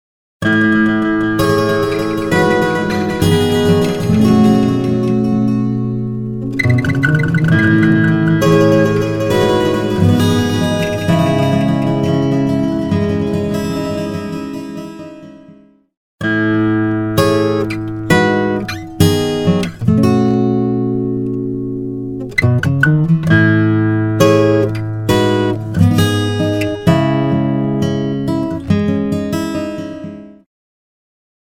UltraTap | Acoustic Guitar | Preset: Slowing Down
Acoustic-Guitar-Slowing-Down.mp3